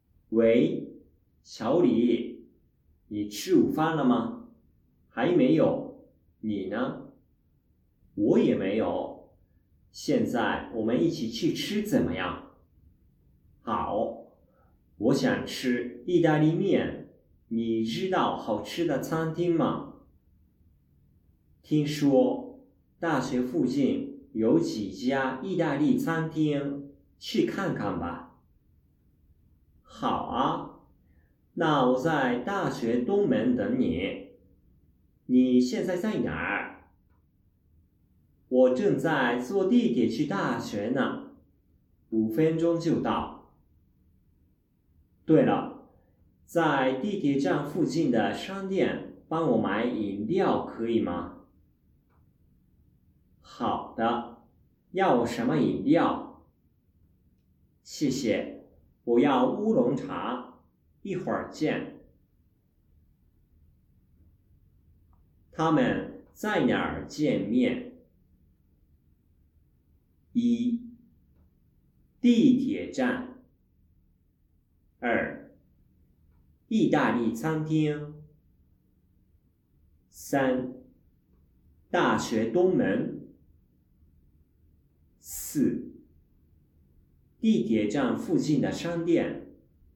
どちらもやや長い音声なので、重要な動詞などのキーワードを判断できることが必要です。
2人の中国語の対話を聞き、中国語で読まれる5つの質問に対して、中国語で読まれる4つの選択肢から最も適当なものを選ぶ問題(5問)
例題 ※サンプルのため、対話は通常よりもやや短いやりとりになっています。